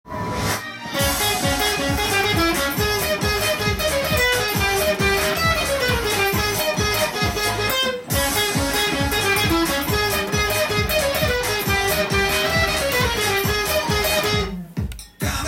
音源に合わせて譜面通り弾いてみました
かなりスピード感がある楽曲になっています。
泥臭い雰囲気がするテンションがメロディーで使われています。
弦移動が激しいギターパートになっていますので
しかし、テンポが速いので跳ねさせるのが大変ですが